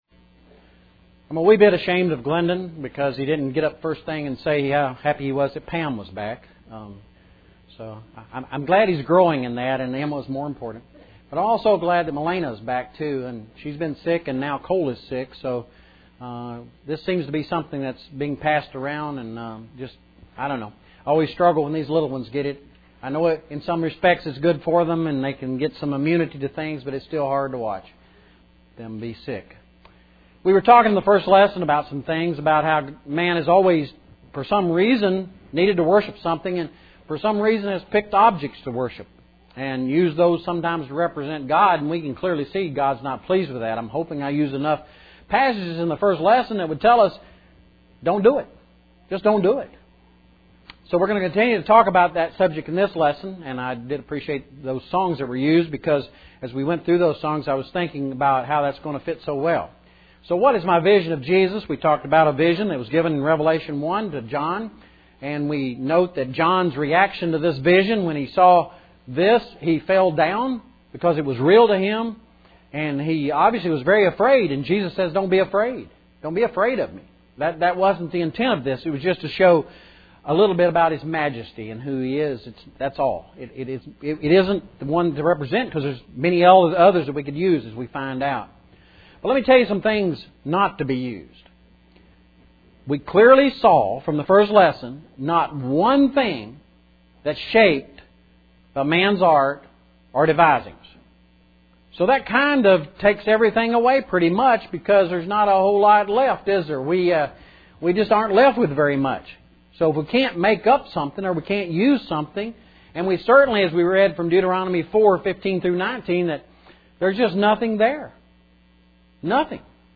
As we continue to work out the bugs in our new electronic recording equipment, we’ll try to provide the transcript or outlines of lessons as we have done below.